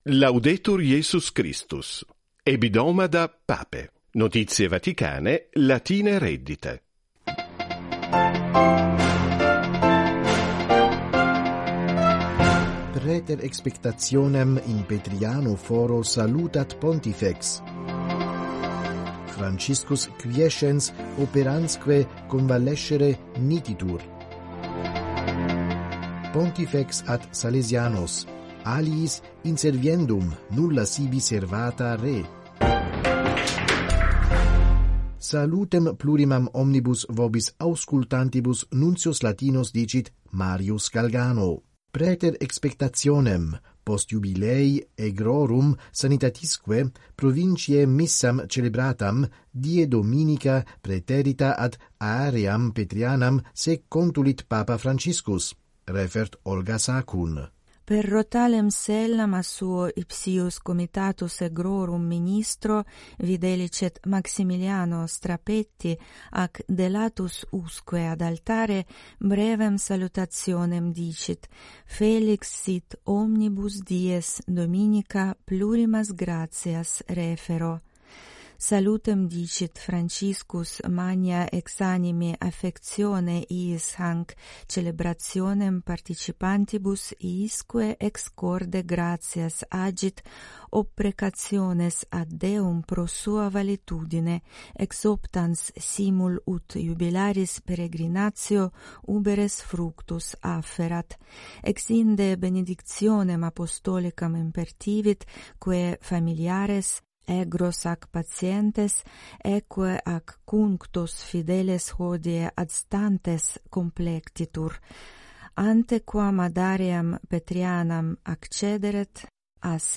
Radio News